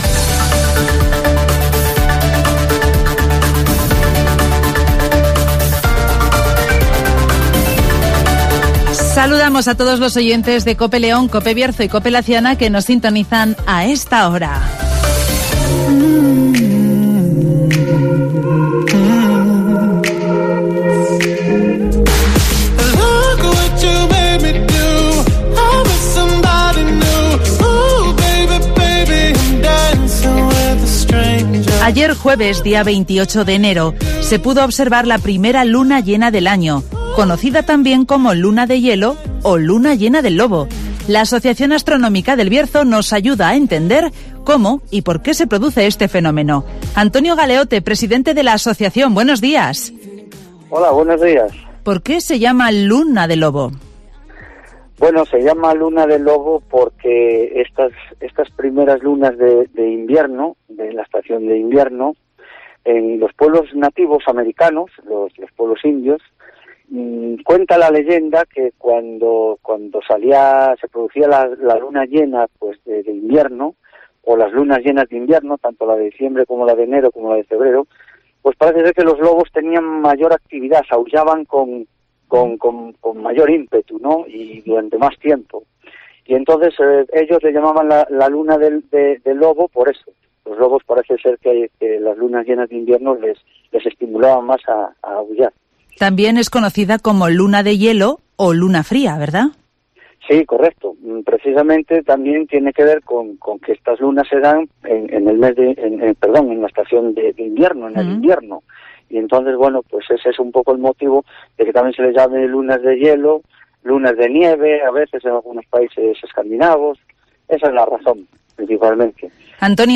Taller digital sobre la luna llena con el Museo de la Energía (Entrevista